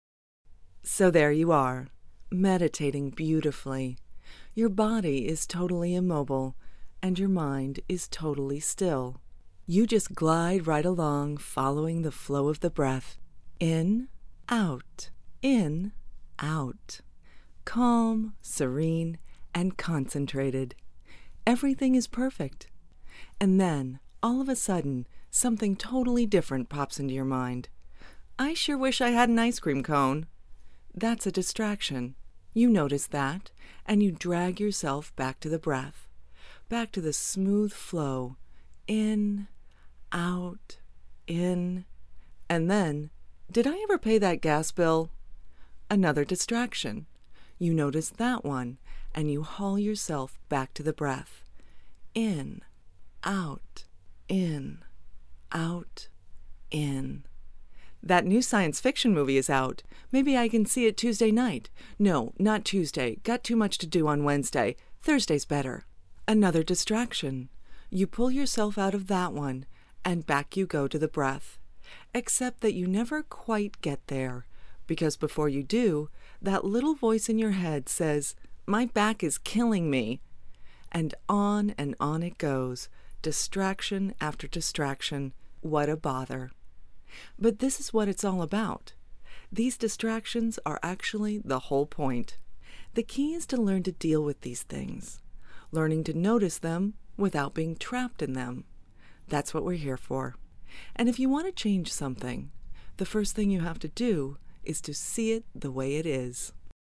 Native English speaker from Los Angeles, California, specializing in commerical, promo, and narration.
Sprechprobe: Sonstiges (Muttersprache):
Conversational, warm, friendly, savvy, smart, quirky, funny, humorous, believable, authoritative, sophisticated, engaging